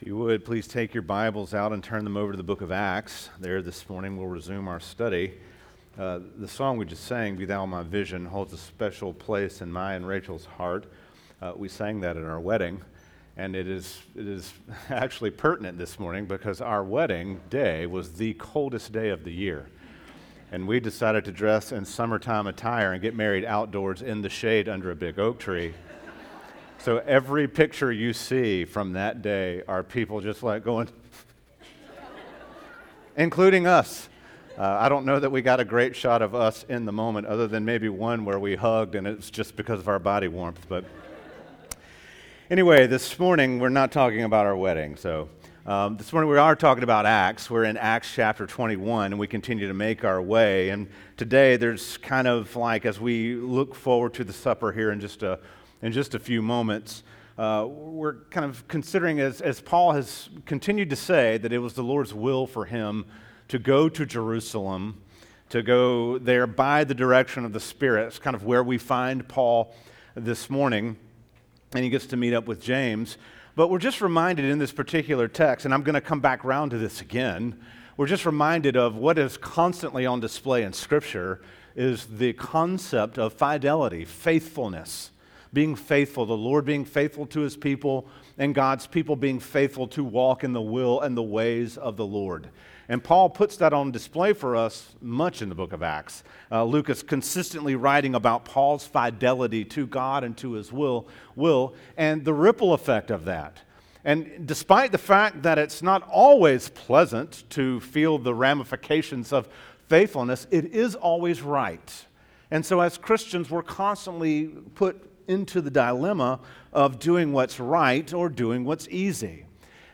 teaches from the series: Acts, in the book of Acts, verses 21:17 - 26